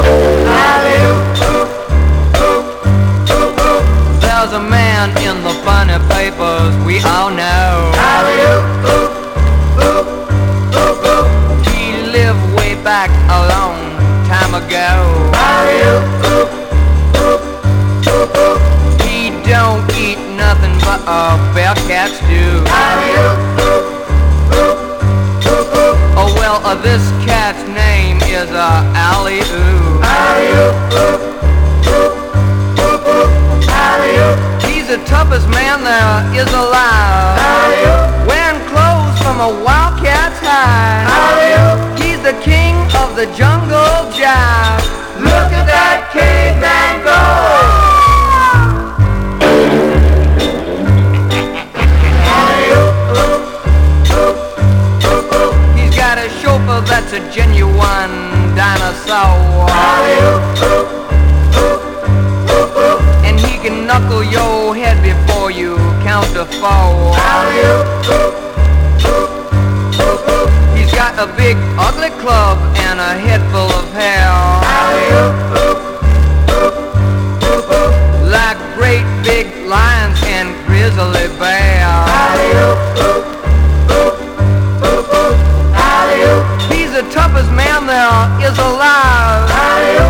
EASY LISTENING / OTHER / NOVELTY
ギコギコ・ゲコゲコとしたギミックがインパクト大なノヴェルティ・ポップソング！
朗らかなカントリー・ポップをベースに